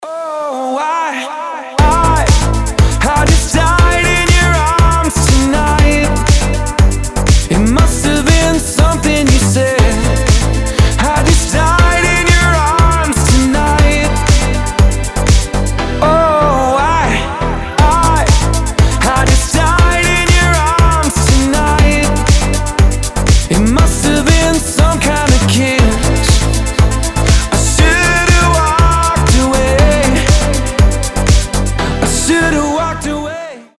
• Качество: 320, Stereo
remix
deep house